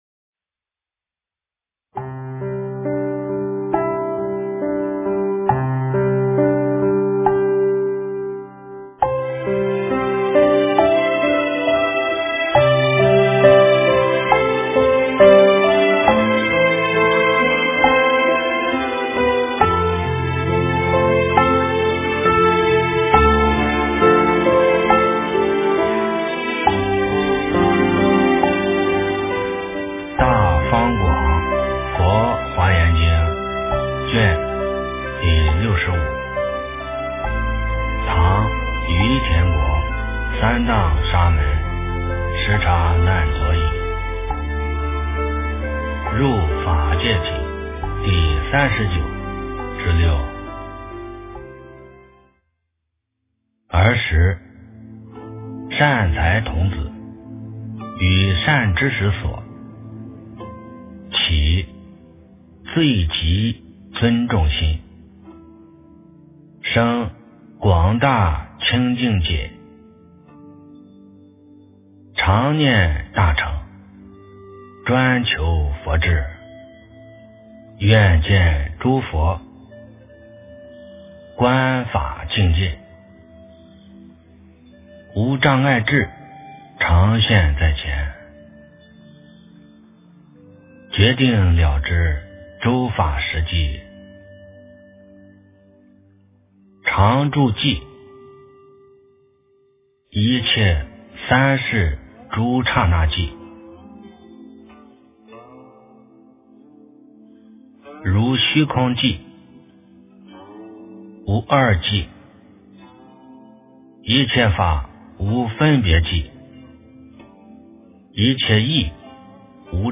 诵经
佛音 诵经 佛教音乐 返回列表 上一篇： 《华严经》62卷 下一篇： 《华严经》67卷 相关文章 南无当来下生弥勒尊佛--无名氏 南无当来下生弥勒尊佛--无名氏...